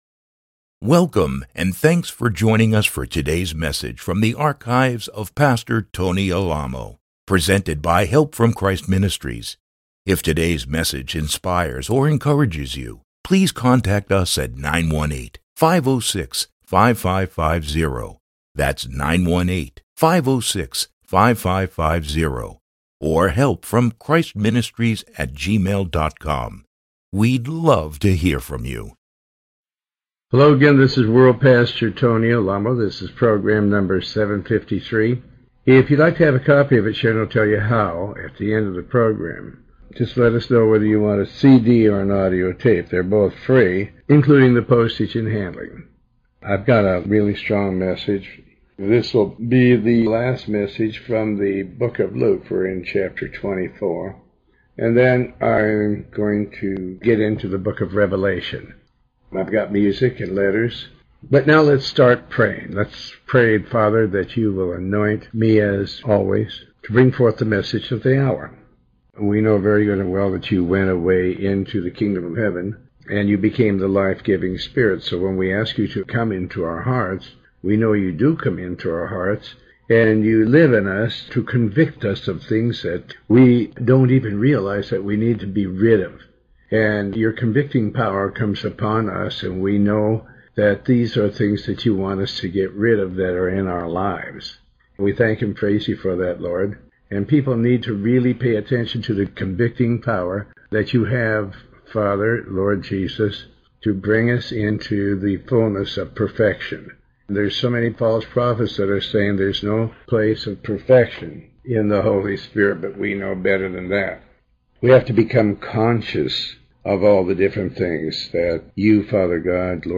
Sermon 753B